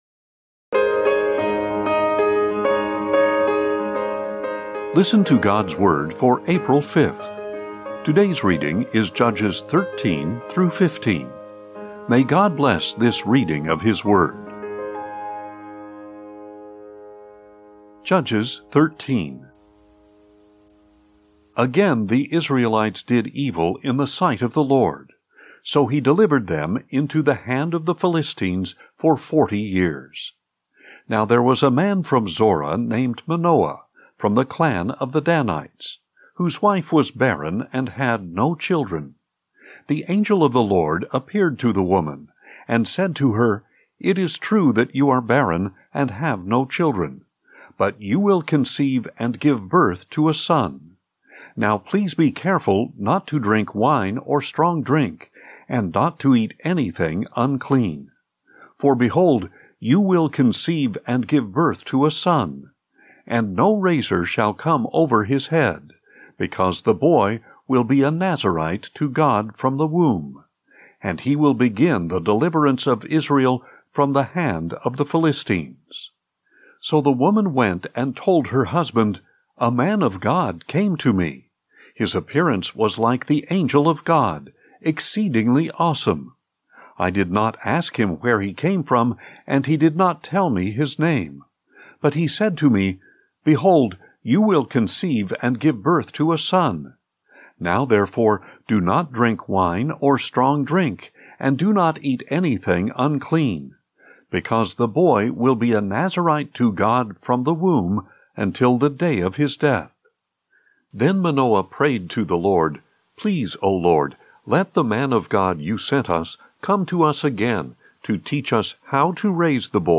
Today's chronological Bible reading is Judges 13-15.